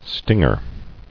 [sting·er]